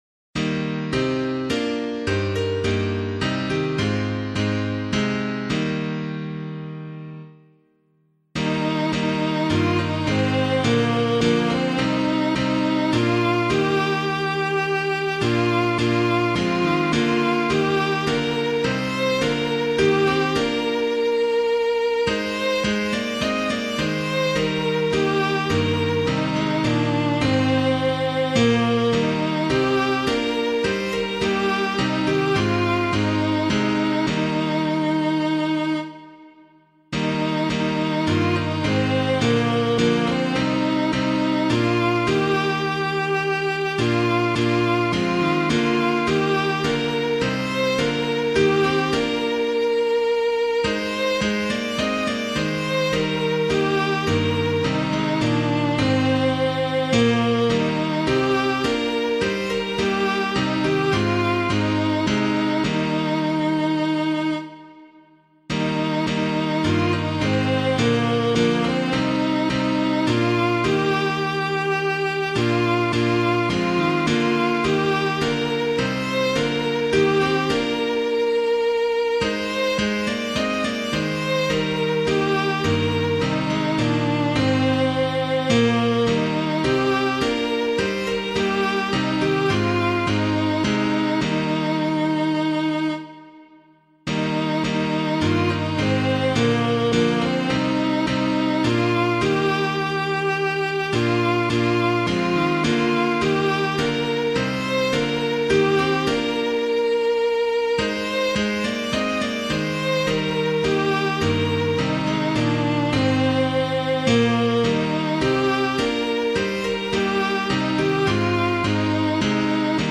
Public domain hymn suitable for Catholic liturgy.
tradional Irish melody
keyboard accompaniment
Be Thou My Vision O Lord of My Heart [Hull - SLANE] - piano.mp3